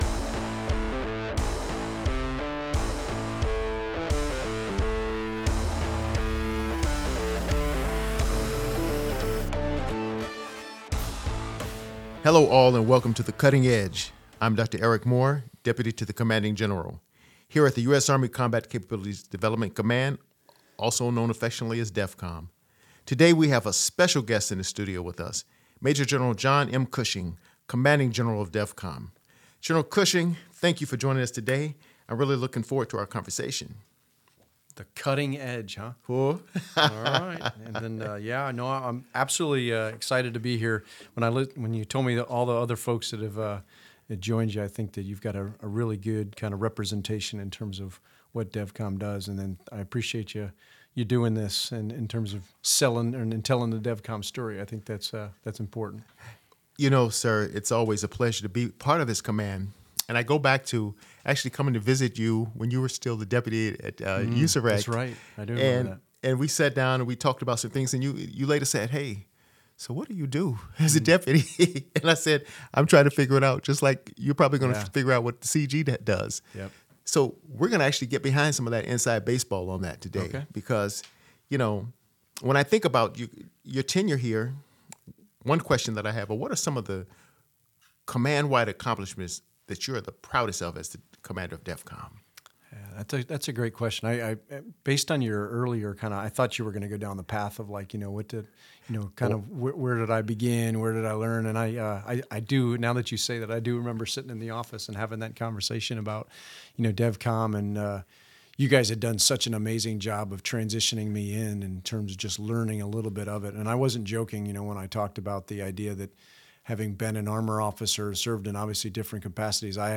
this podcast series is dedicated to interviews with leaders from all levels across DEVCOM, its centers, and its research laboratory.